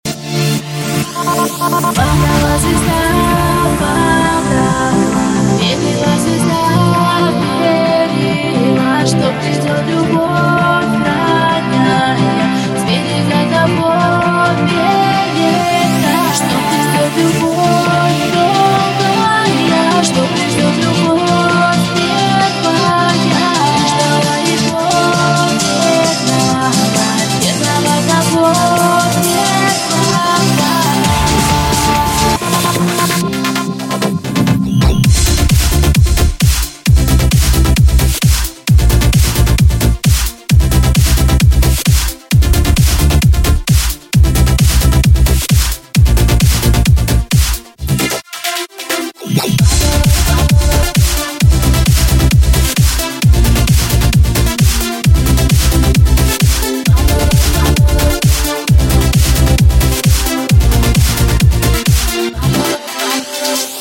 • Качество: 150, Stereo
женский вокал
dance
Electronic
Club House
electro house
Melodic